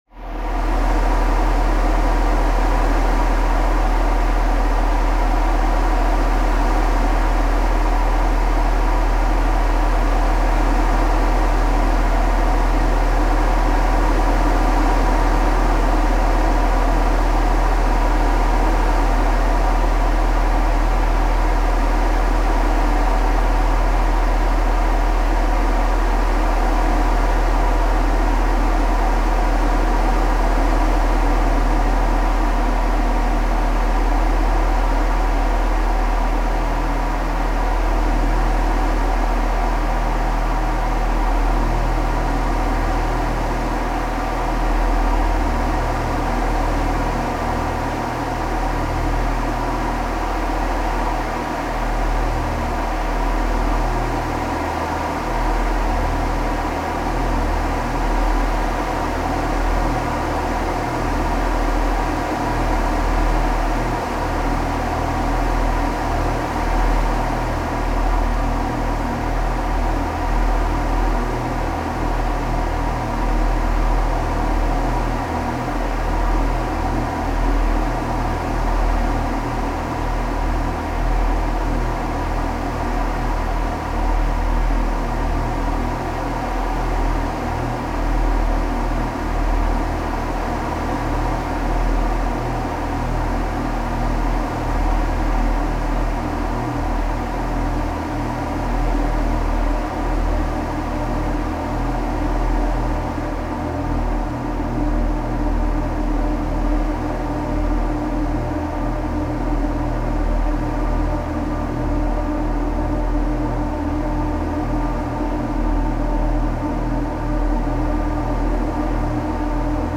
please to enjoy the following bullshit synthesizer music and such forth
improvisation